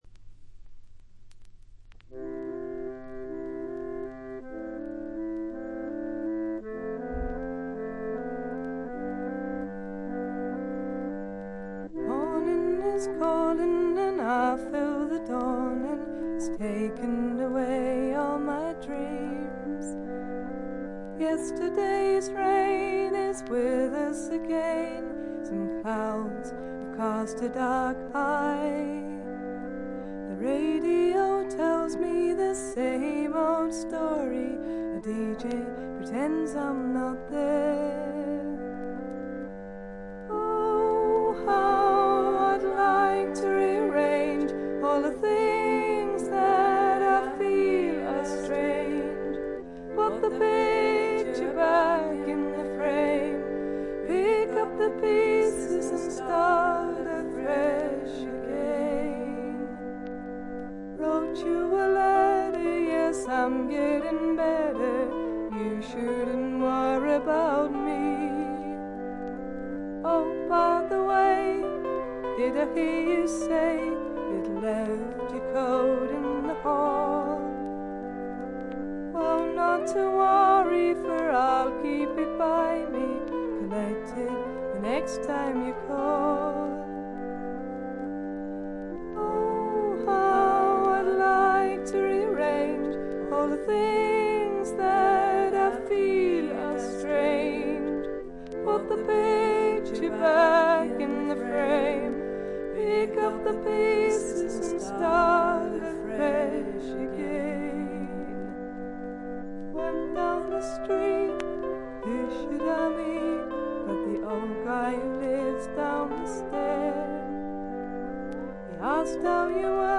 試聴曲は現品からの取り込み音源です。
Vocals, Harmonium, Electric Guitar